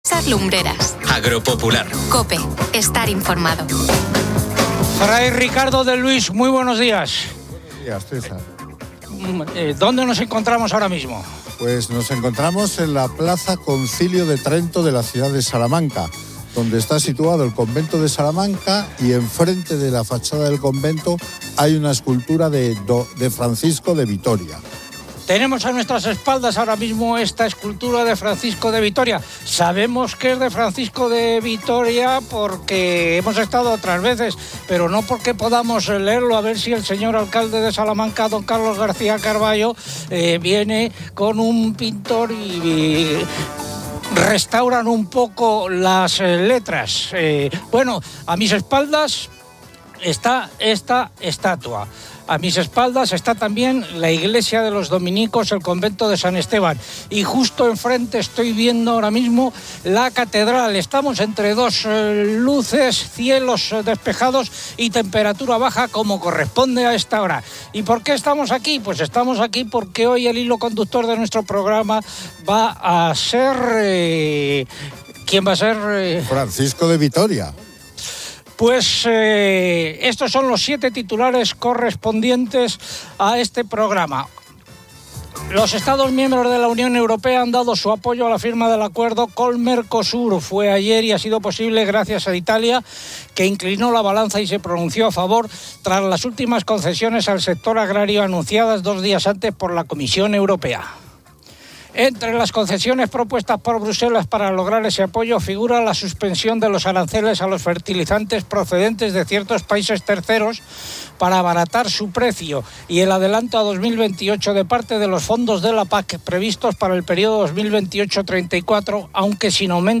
El programa Agro Popular se emite desde Salamanca y profundiza en la figura de Francisco de Vitoria, fundador de la Escuela de Salamanca y pionero del derecho internacional público.